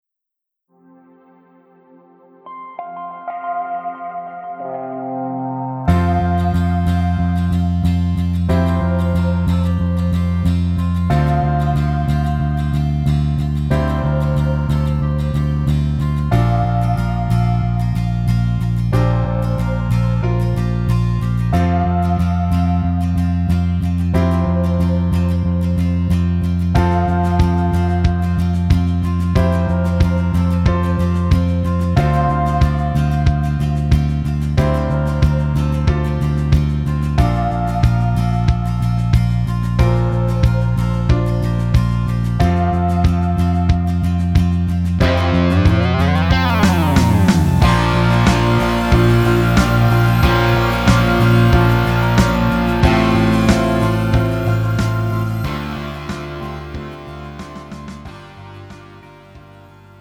음정 여자키
장르 가요 구분 Pro MR